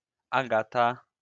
Wymowa
[aˈɡata]